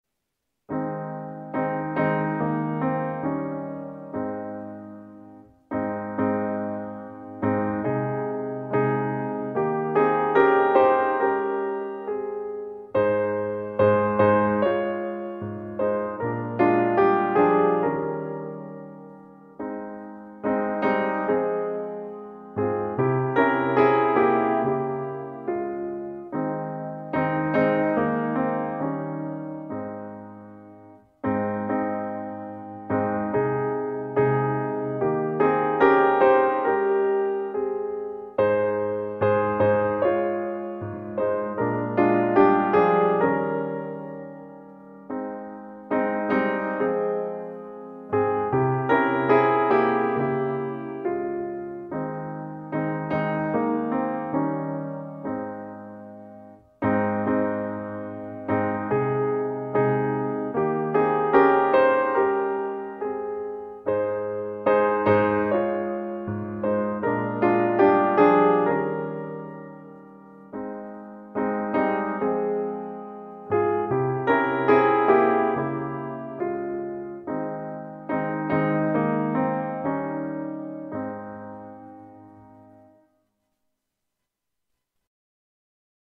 HYMN: Christina Rossetti